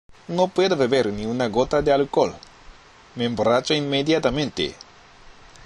Me の最後の 「e」と emborracho のアタマの「e」がくっついて聞こえるので
確かに borracho と聞こえてしまいそうです。